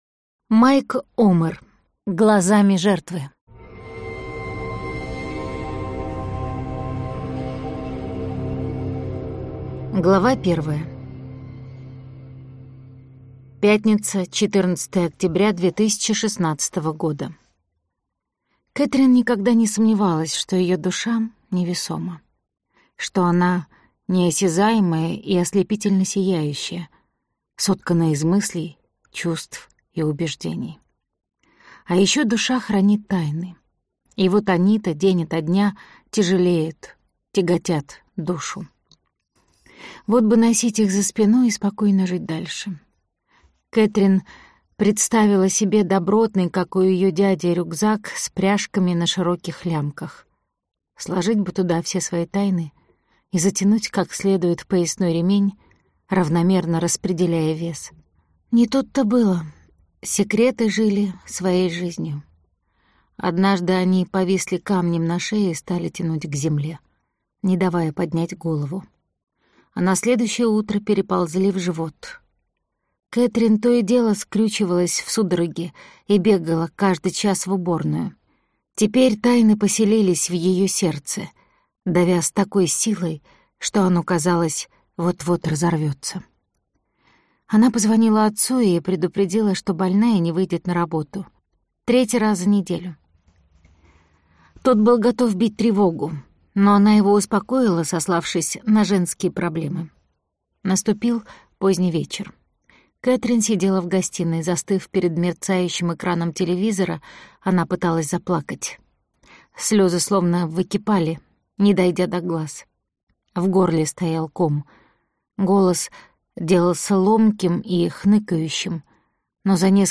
Аудиокнига Глазами жертвы - купить, скачать и слушать онлайн | КнигоПоиск